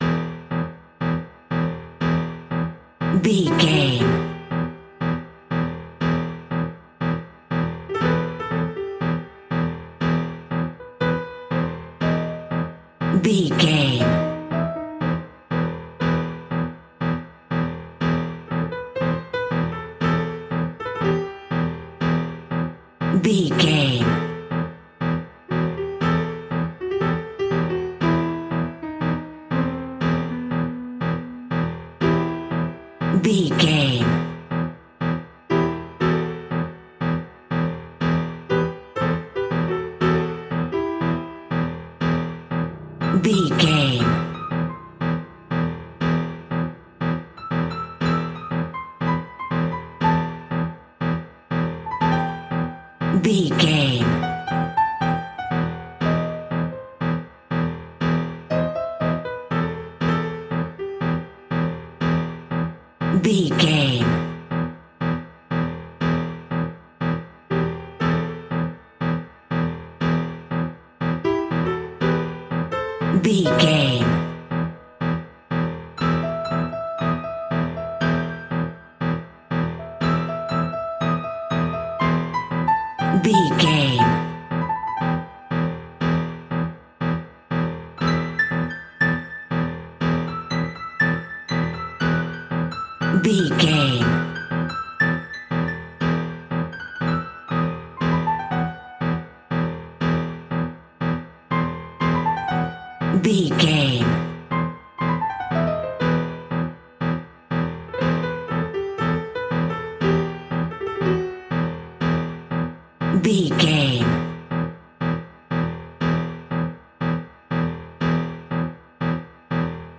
Aeolian/Minor
tension
ominous
dark
haunting
eerie